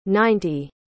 A pronúncia é simples: “nain-ti”.
• Ninety (90): Pronúncia: nain-ti.
Ninety.mp3